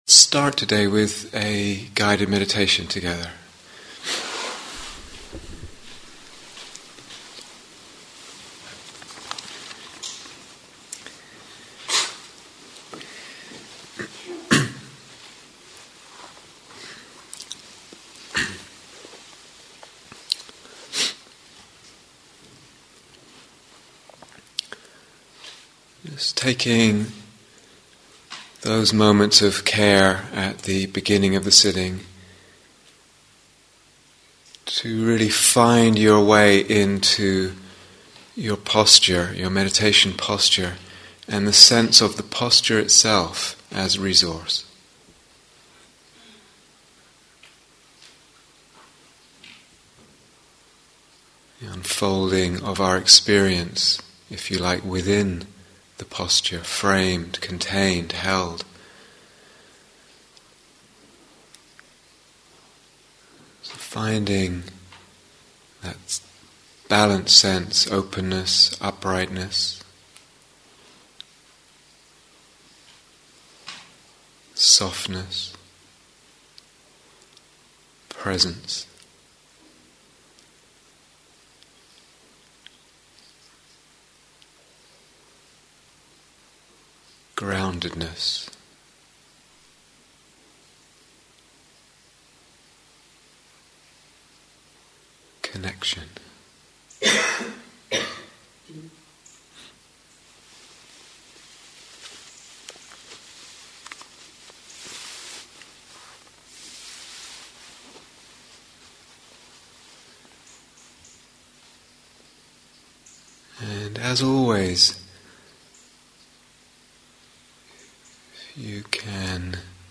Working with the Emotional Body (Instructions and Guided Meditation: Day Six)